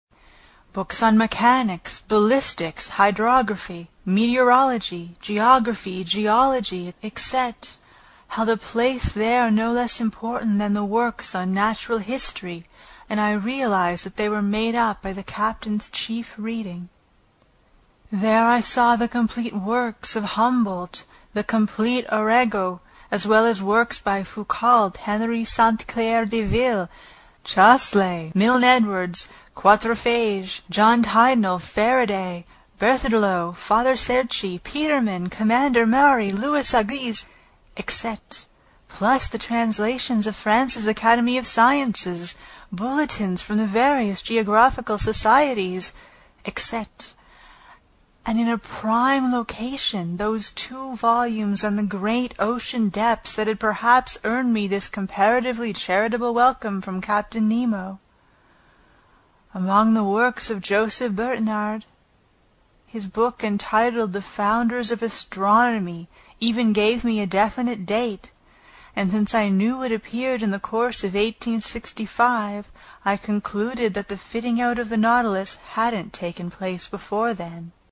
英语听书《海底两万里》第153期 第11章 诺第留斯号(4) 听力文件下载—在线英语听力室
在线英语听力室英语听书《海底两万里》第153期 第11章 诺第留斯号(4)的听力文件下载,《海底两万里》中英双语有声读物附MP3下载